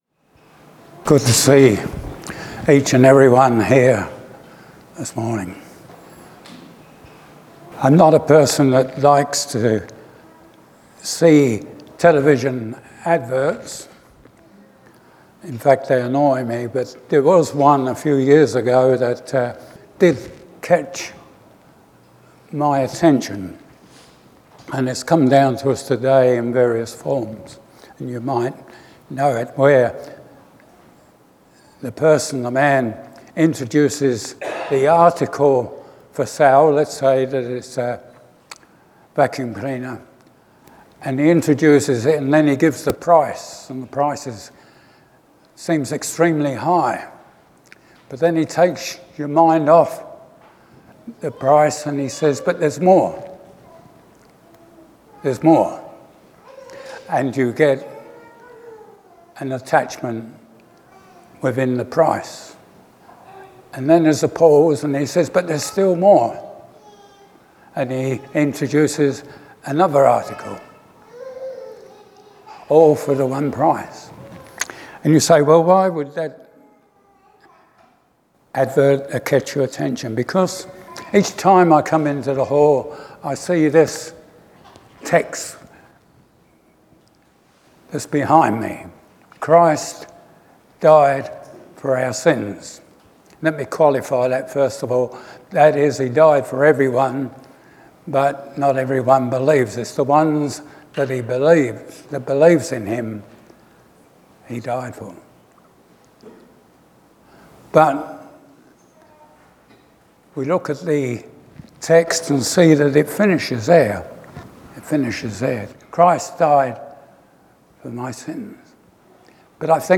Service Type: Special Event Topics: Easter , Good Friday , Gospel